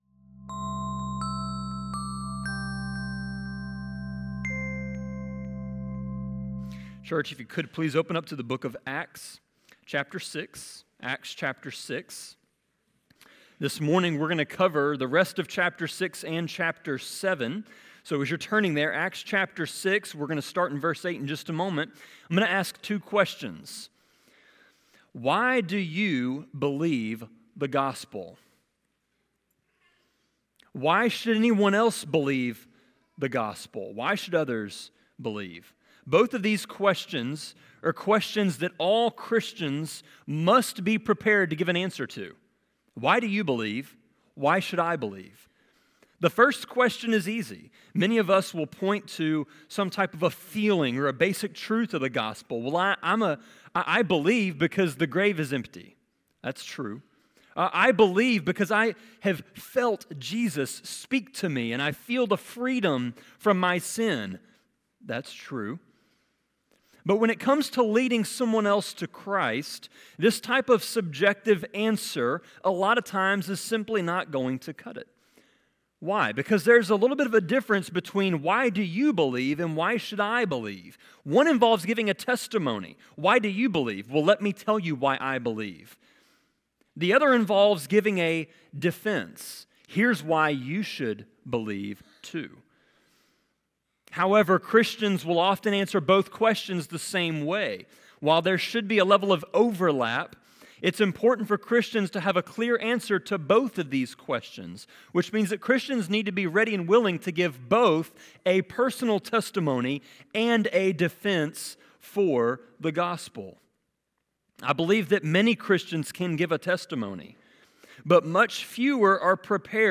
Sermon-24.3.24.m4a